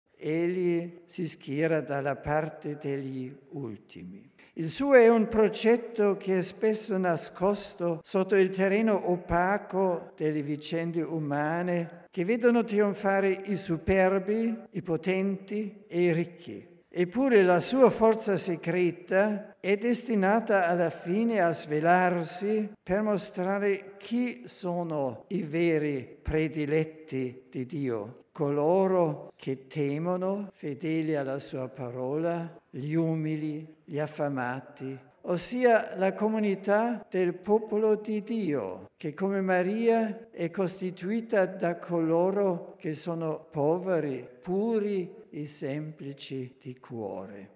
(RV - 15 feb 2006) Cu audienţa generală de miercuri, pe care Benedict XVI a început-o în Bazilica Sfântul Petru plină de pelerini şi a continuat-o apoi în Aula Paul VI din Vatican, Papa a încheiat prin comentarea cântării „Magnificat”, „Sufletul meu îl preamăreşte pe Domnul”, reflecţia asupra Liturgiei Laudelor şi Vesperelor, începută de Ioan Paul II în 2001.